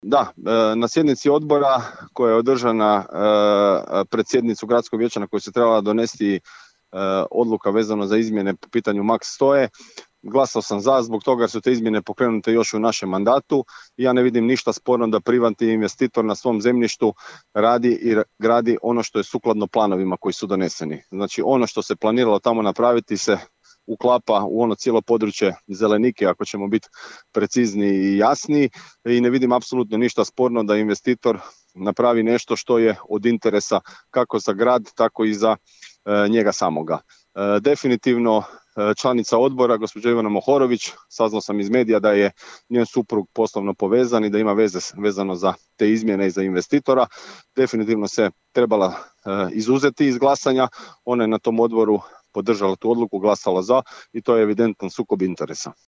Izjava Robert Cvek